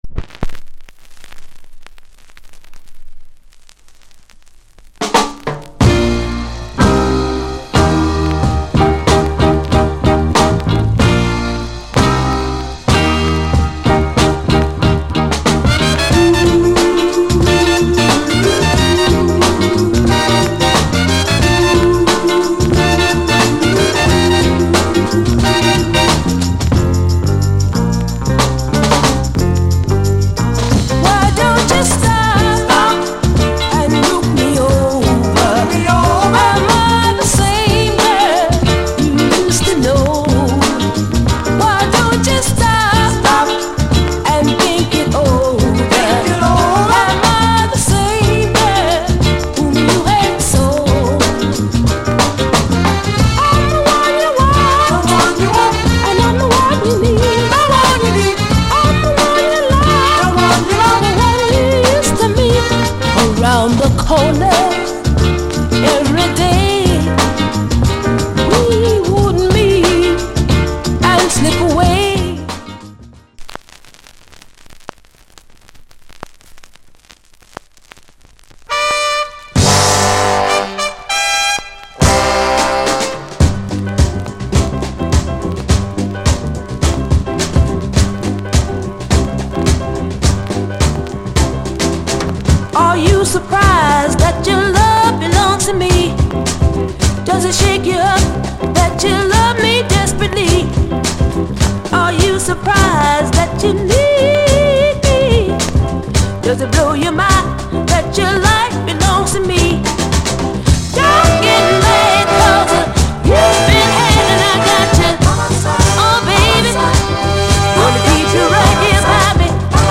* Soulful Strat ** Rare JA Issue